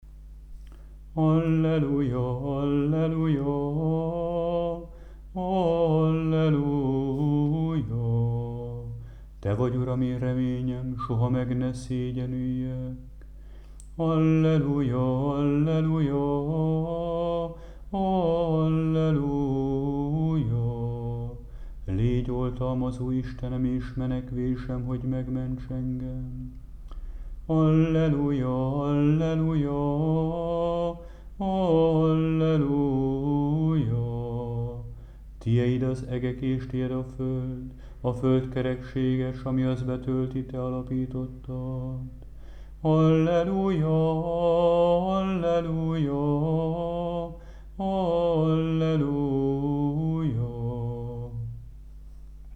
14. Alleluja 3. és 4. hang, feltám. és aug. 6..mp3